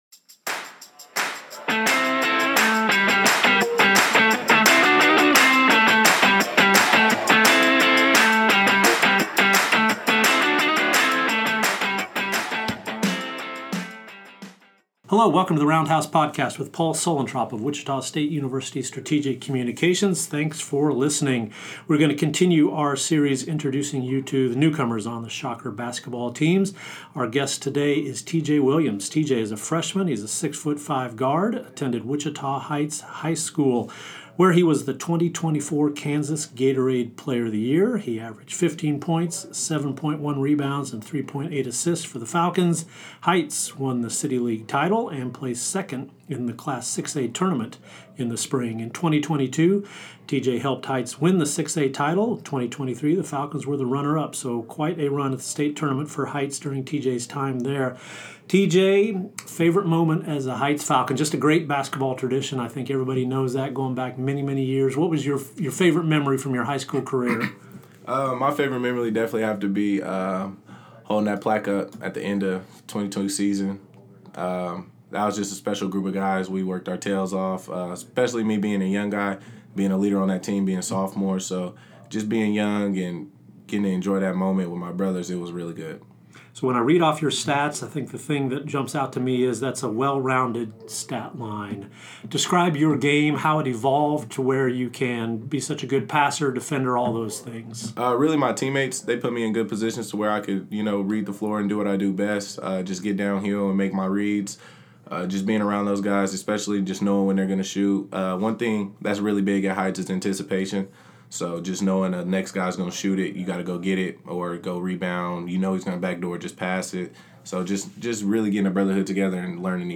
Wichita State basketball freshmen